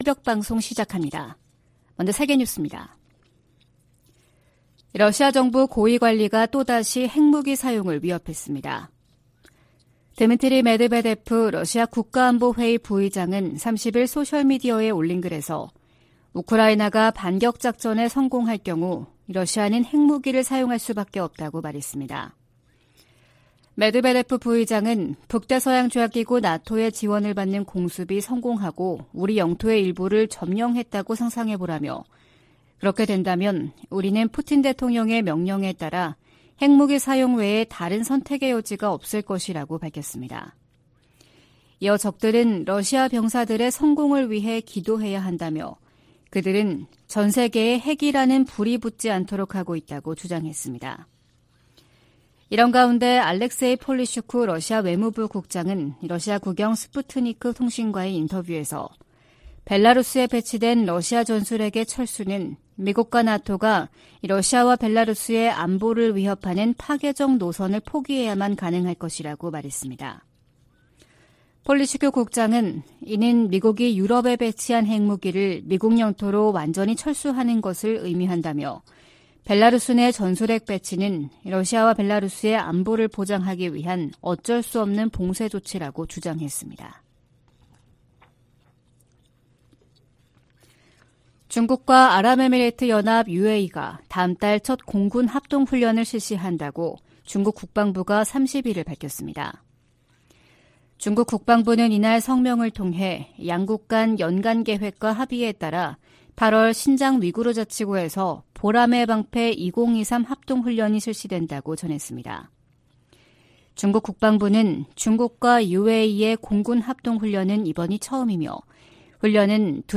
VOA 한국어 '출발 뉴스 쇼', 2023년 8월 1일 방송입니다. 백악관이 미한일 3국 정상회담 개최를 공식 발표하며 북한 위협 대응 등 협력 확대 방안을 논의할 것이라고 밝혔습니다. 미 국무부는 줄리 터너 북한인권특사 지명자에 대한 상원 인준을 환영했습니다. 미 상원이 2024회계연도 국방수권법안을 통과시켰습니다.